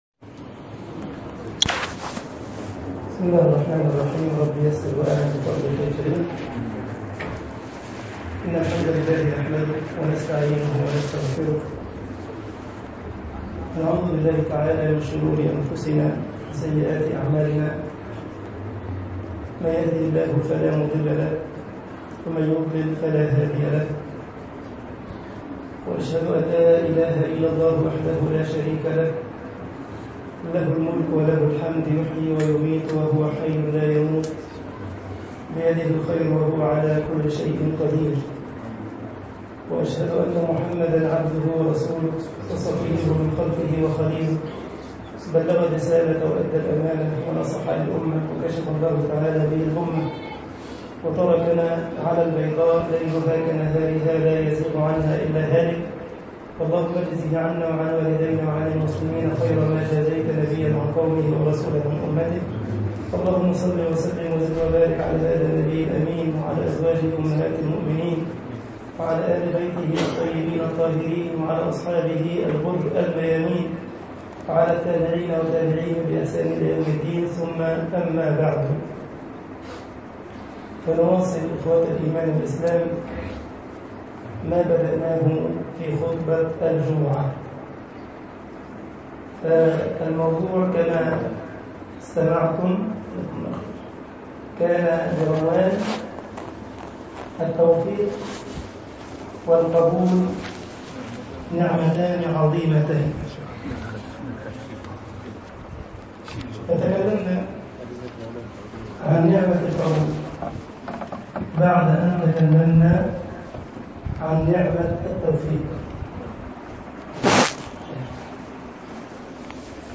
التوفيق والقبول نعمتان عظيمتان - محاضرة